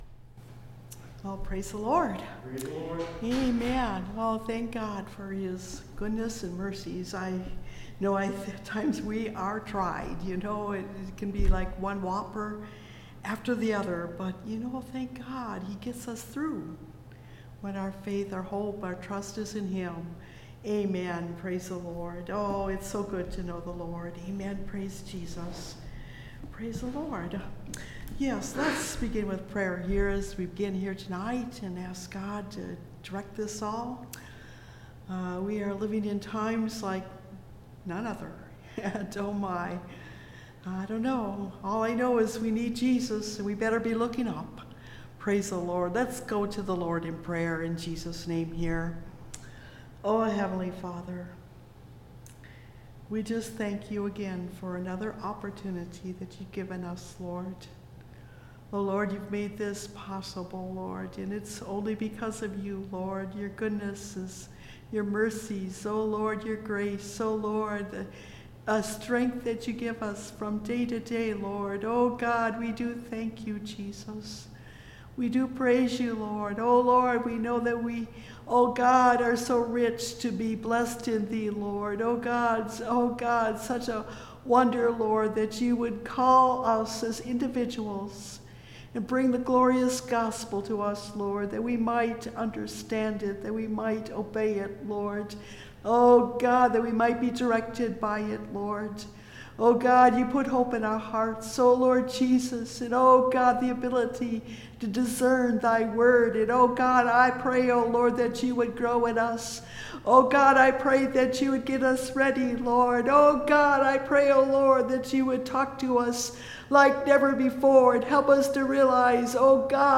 They Turned The World Upside Down – Part 3 (Message Audio) – Last Trumpet Ministries – Truth Tabernacle – Sermon Library